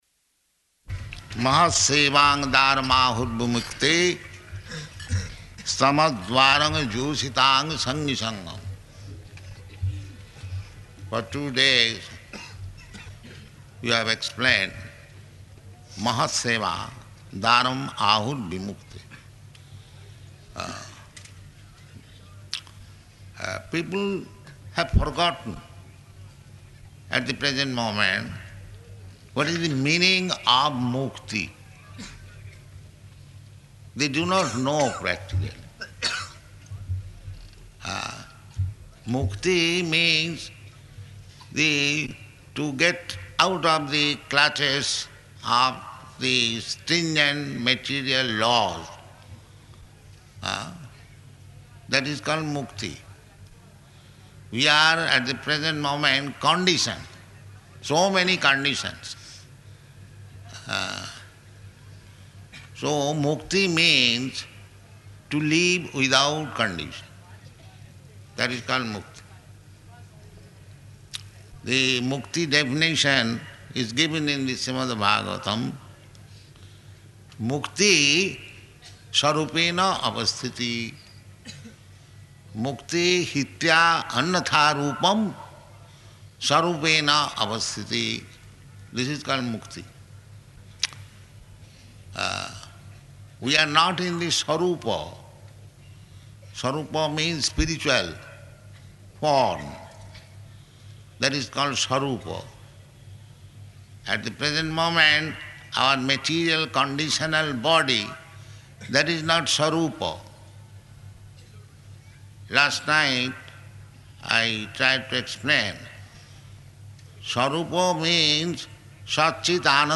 Location: Hyderabad